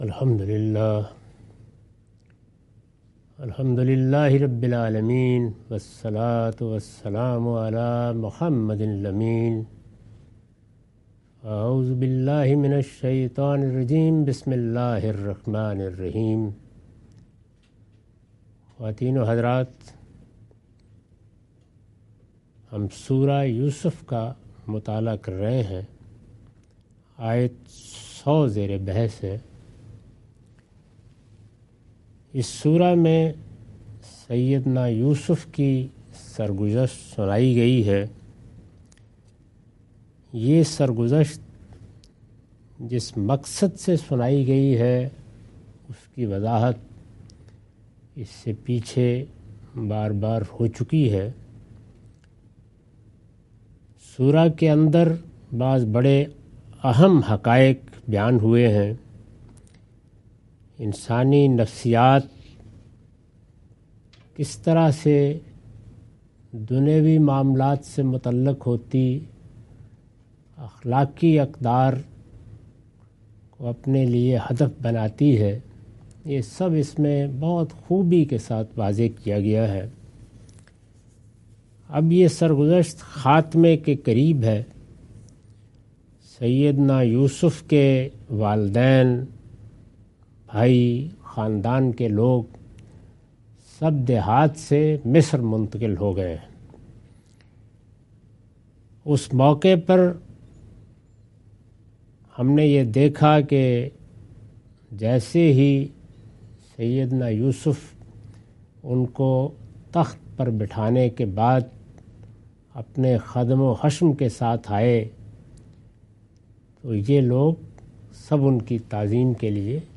Surah Yousuf - A lecture of Tafseer-ul-Quran – Al-Bayan by Javed Ahmad Ghamidi. Commentary and explanation of verses 100-102.